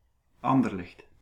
Dutch pronunciation of "Anderlecht" (Belgium)